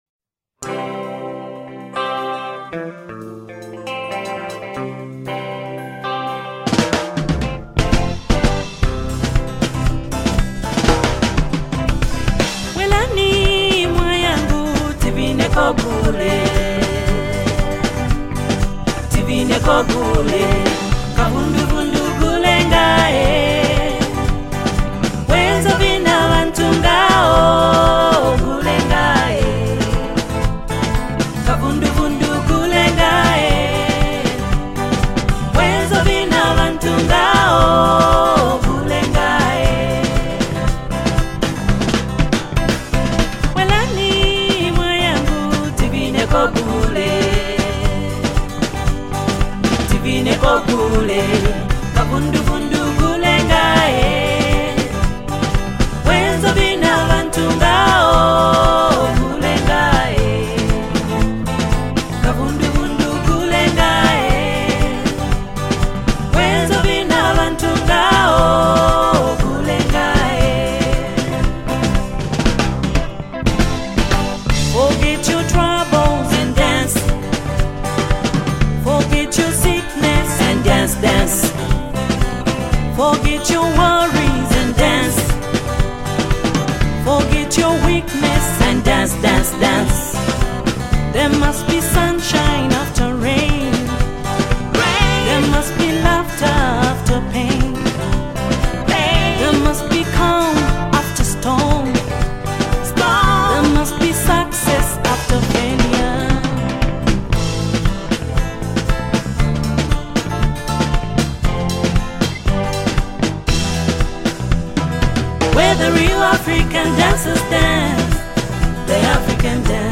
full of emotion